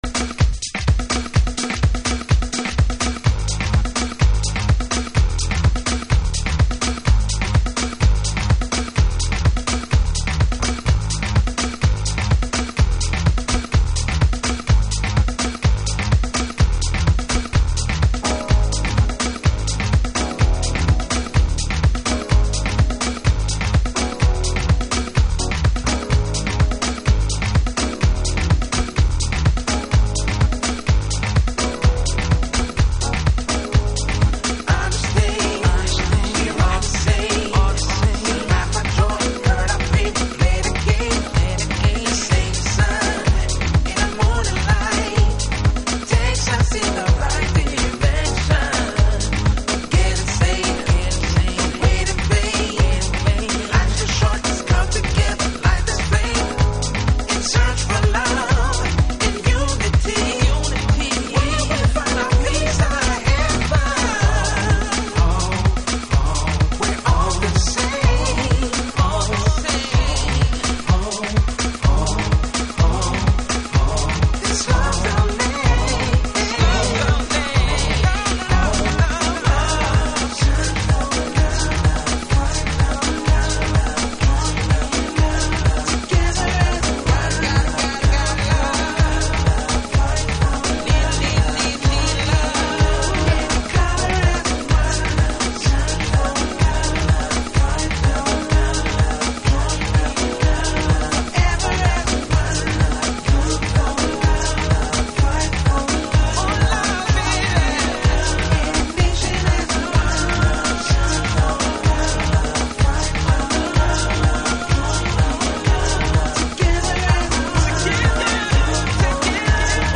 House / Techno
Vocal